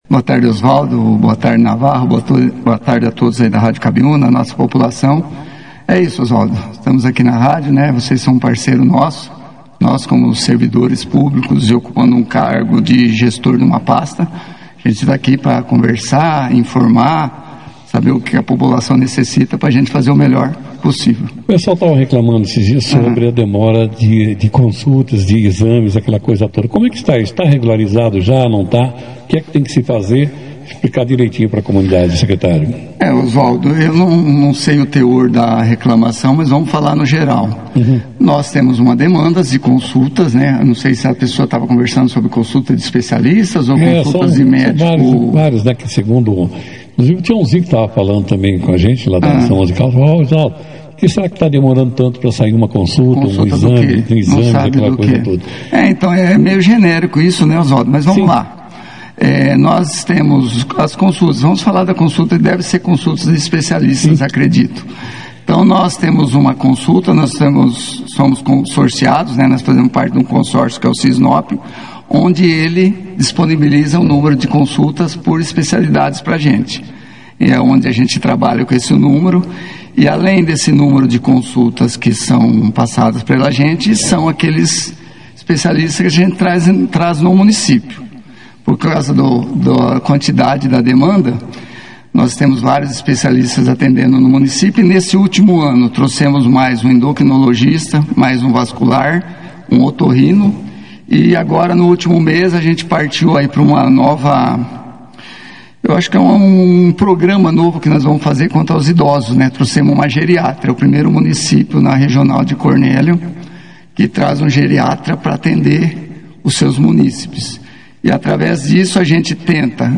O Secretário de Saúde de Bandeirantes, Alexandro Beretta (foto), esteve presente ao vivo na 2ª edição do Jornal Operação Cidade, nesta quarta-feira, 26 de Novembro.
Durante a entrevista, ele abordou pontos importantes sobre a oferta de serviços médicos no município e destacou as ações da Secretaria de Saúde para aprimorar a qualidade do atendimento à população.
secretario-de-saude-de-Bandeirantes-responde-perguntas-de-usuarios-.mp3